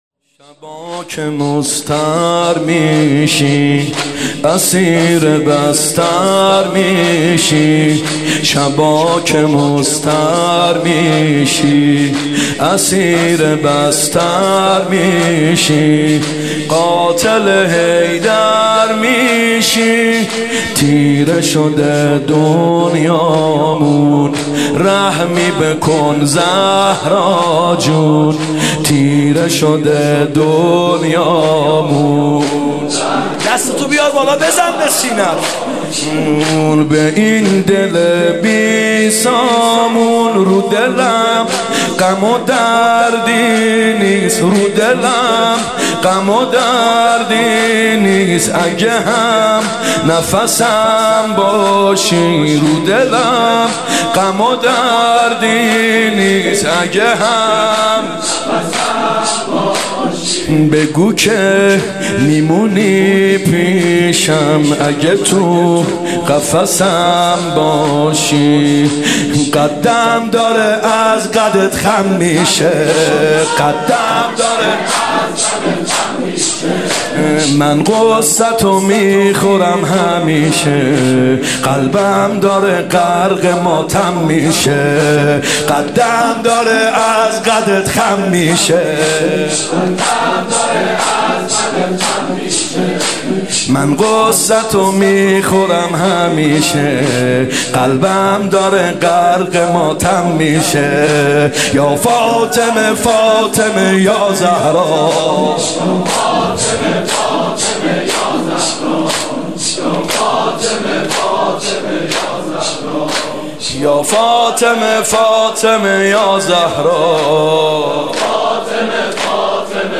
فاطمیه دوم زمینه ( شبا که مضطر میشی
فاطمیه دوم هیئت یامهدی (عج)